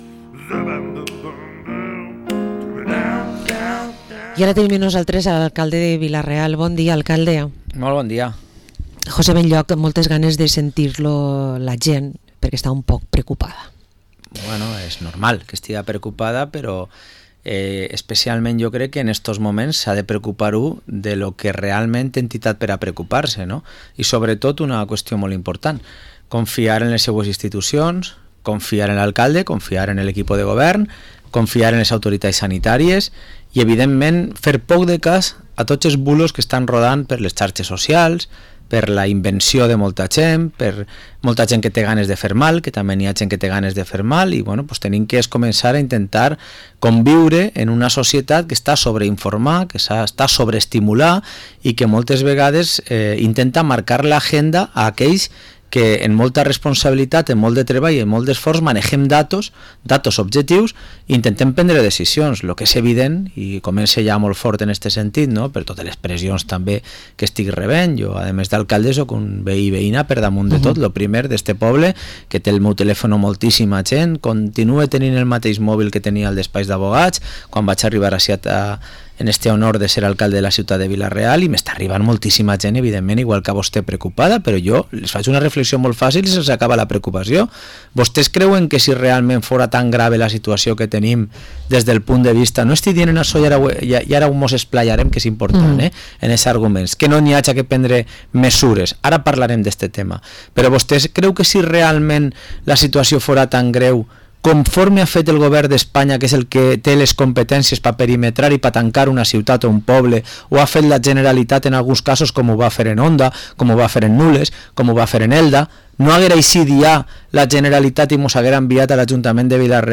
Entrevista a José Benlloch, Alcalde de Vila-real